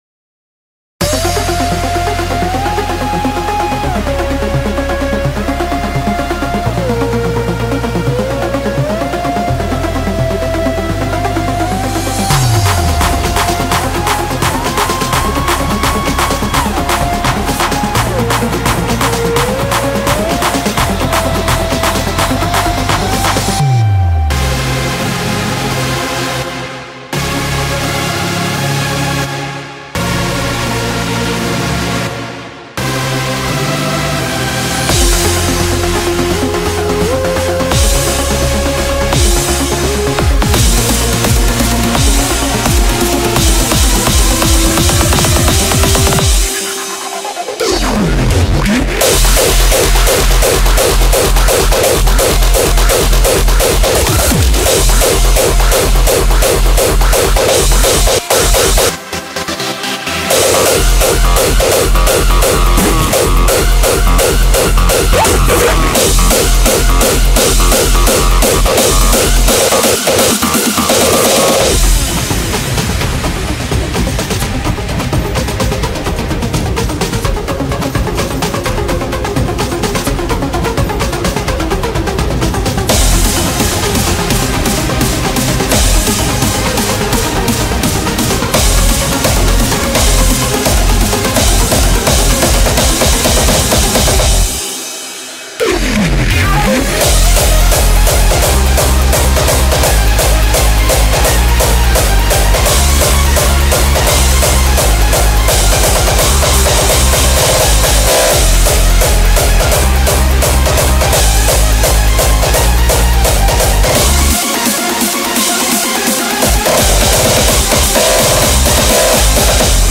BPM170